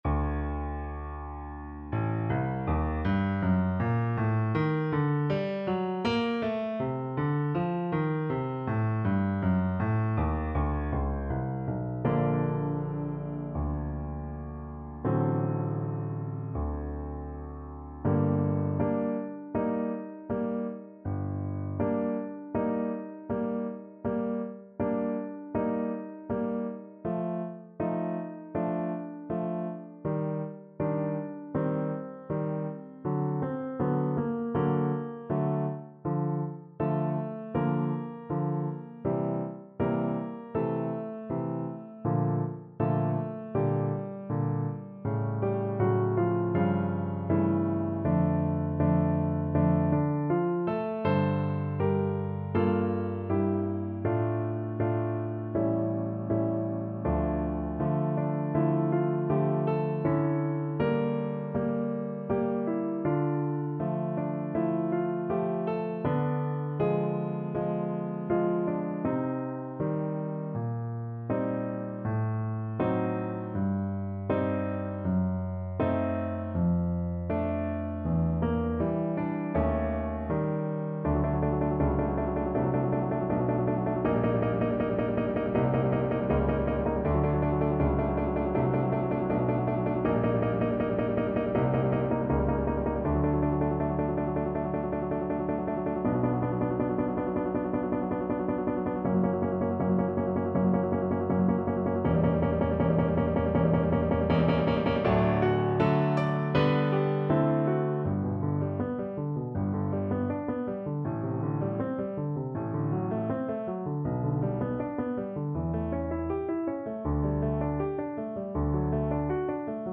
Play (or use space bar on your keyboard) Pause Music Playalong - Piano Accompaniment Playalong Band Accompaniment not yet available transpose reset tempo print settings full screen
G minor (Sounding Pitch) A minor (Trumpet in Bb) (View more G minor Music for Trumpet )
~ = 100 Molto moderato =80
Classical (View more Classical Trumpet Music)